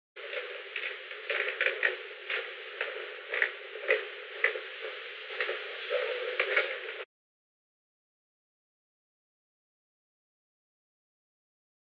On February 8, 1973, President Richard M. Nixon and unknown person(s) met in the President's office in the Old Executive Office Building at an unknown time between 1:28 pm and 1:49 pm. The Old Executive Office Building taping system captured this recording, which is known as Conversation 409-021 of the White House Tapes.
The President met with an unknown man.